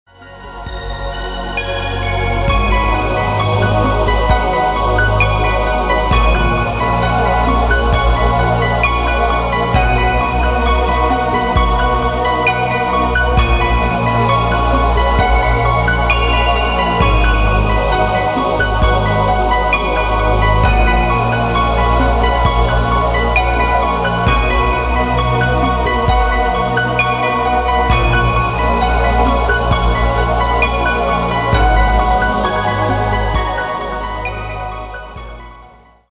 • Качество: 192, Stereo
спокойные
без слов
инструментальные